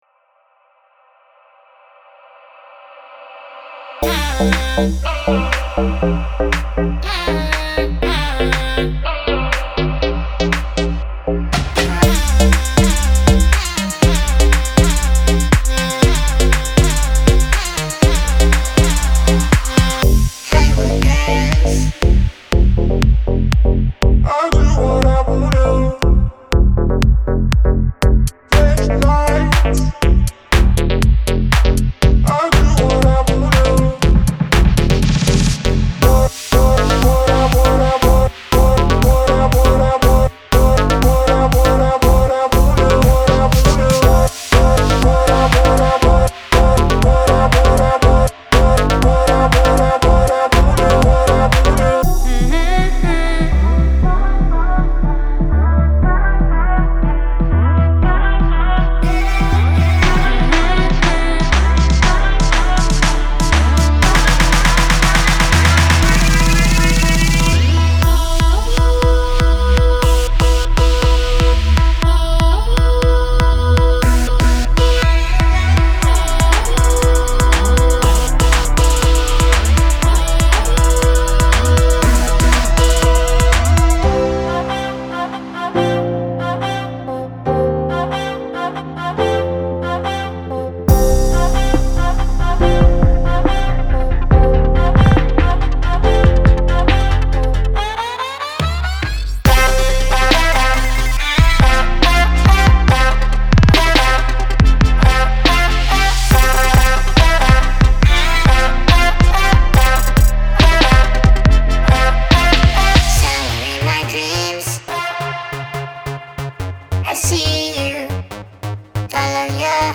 仅包括人声。其他声音仅用于演示目的。
.030x声排（已处理)(12OBPM)- (30x干-40x湿)
.076x声部-(14OBPM)- (36x干燥-40x湿)